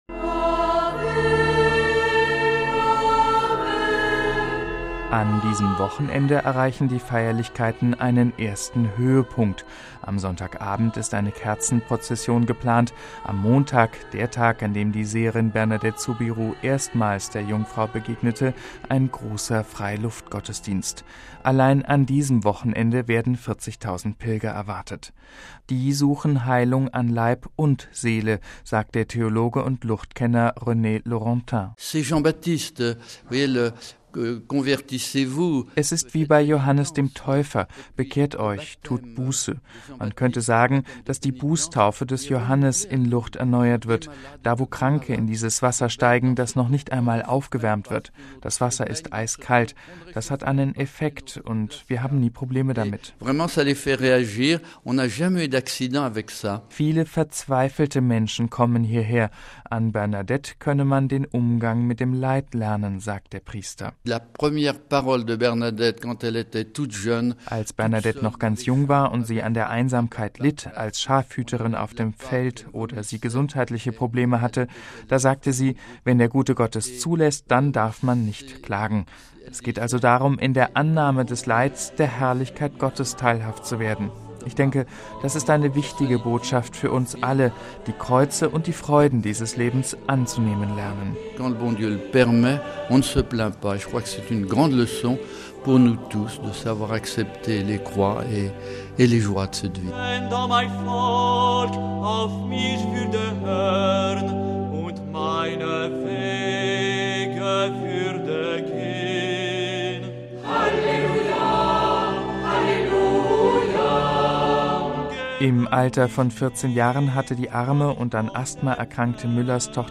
Ein eigener Pilgerweg mit vier Stationen wurde eingerichtet, sagt der Bischof von Lourdes, Jacques Perrier.